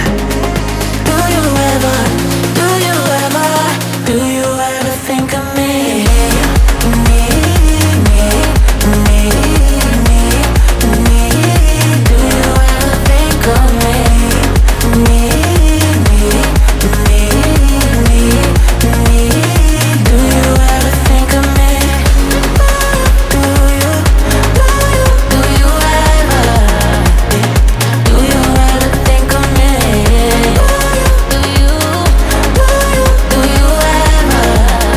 Скачать припев
Afro House